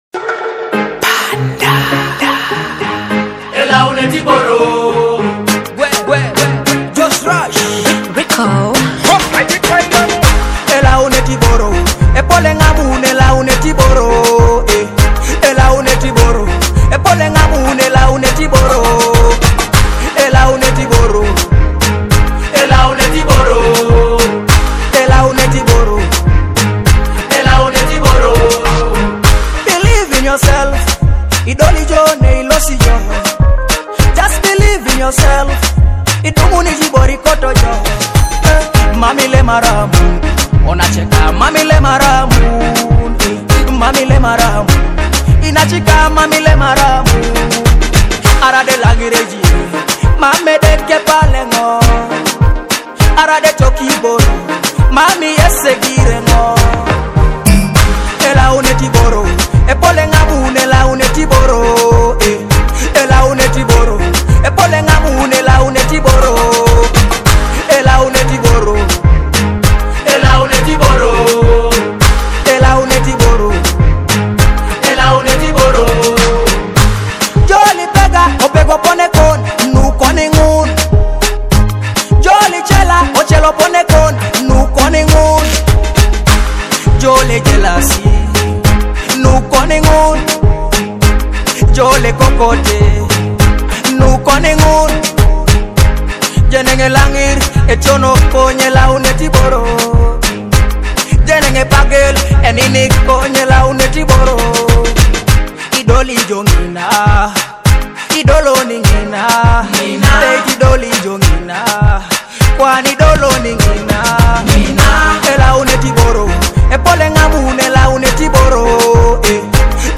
Teso dancehall track